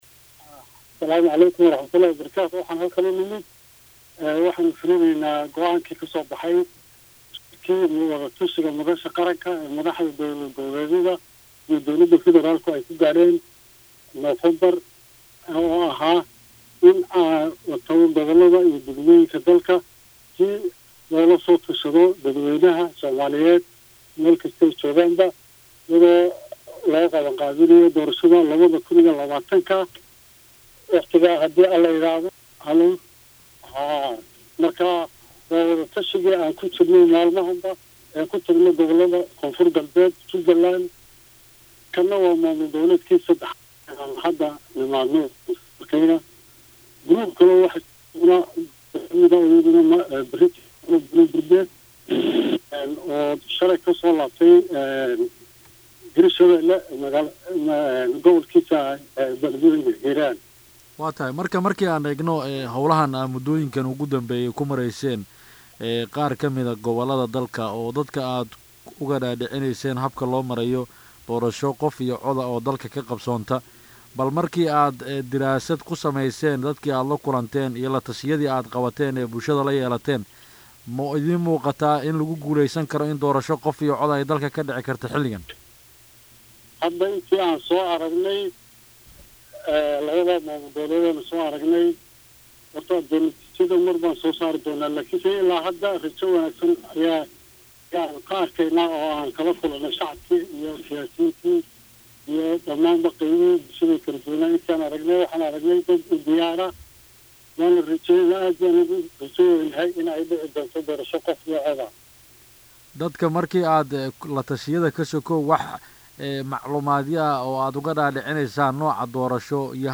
Maxamad Daahir Xubin ka Mid ah gudiga Madaxa Banaan ee Doorashooyinka oo la hadlay Radio Muqdisho Codka Jamhuuriyadda Soomaaliya ayaa Faah faahin ka bixiyay Socdaalka ay ku gaareen Magaalada Jowhar.